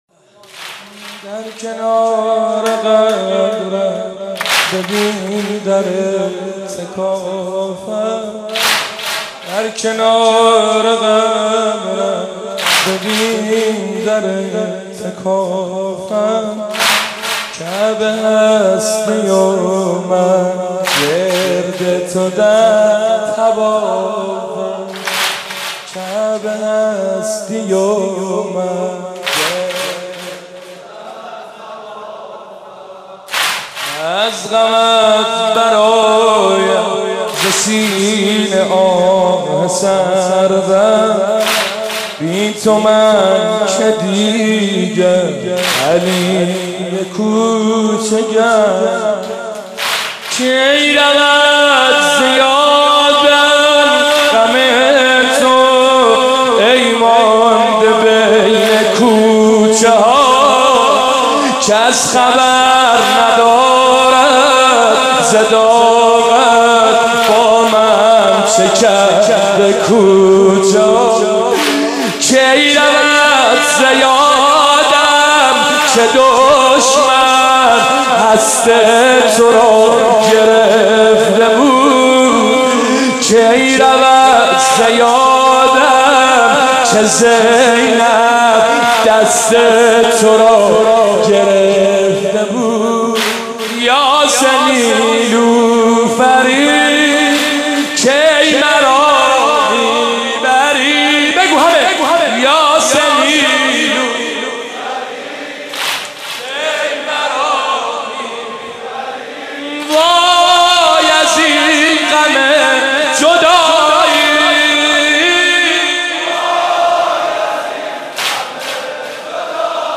مناسبت : شهادت حضرت فاطمه زهرا سلام‌الله‌علیها1
قالب : سنگین